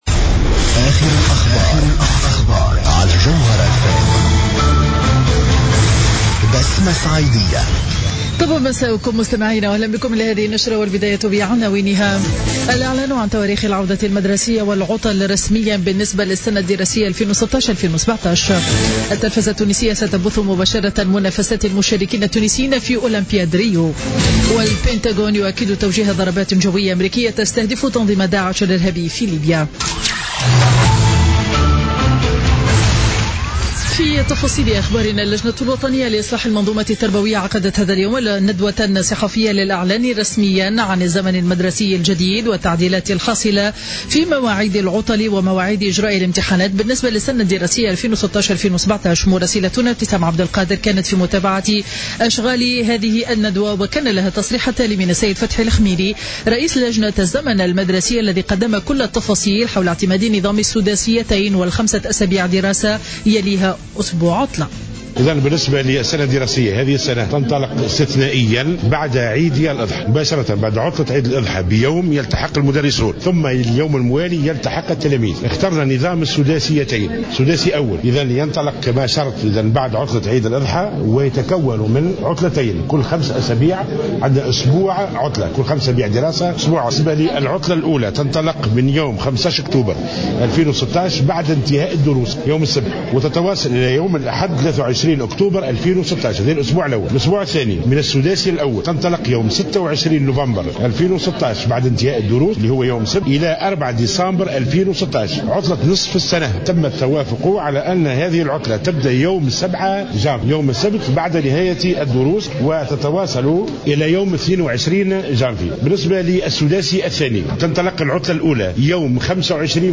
Journal Info 19h00 du lundi 1er août 2016